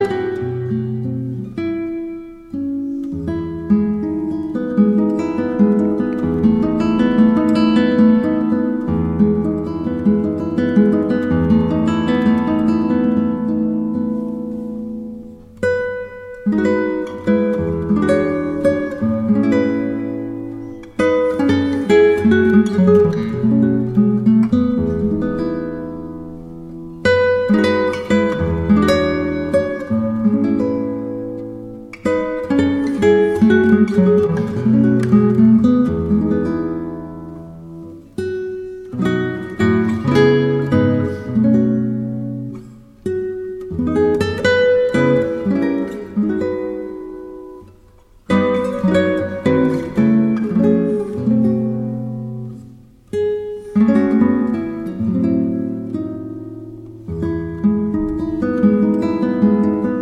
Instrumentalversionen beliebter Lobpreislieder
• Sachgebiet: Praise & Worship